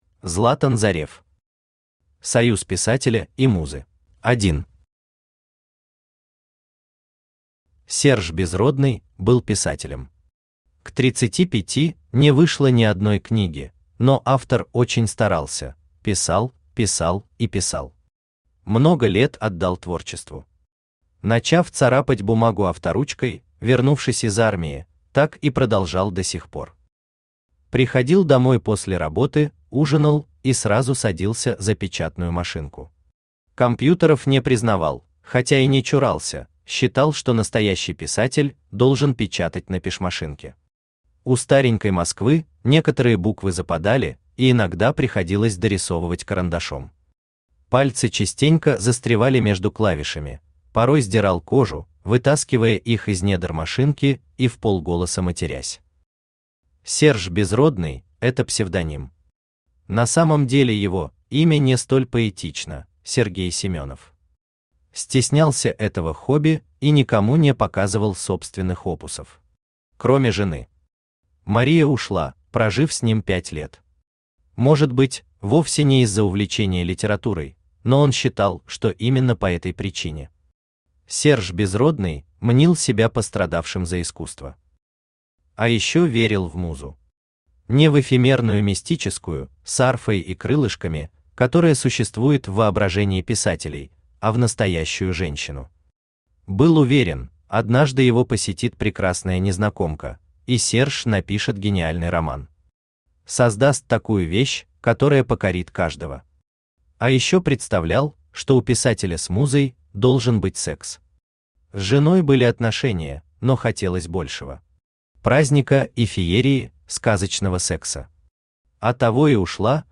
Аудиокнига Союз писателя и музы | Библиотека аудиокниг
Aудиокнига Союз писателя и музы Автор Златан Зорев Читает аудиокнигу Авточтец ЛитРес.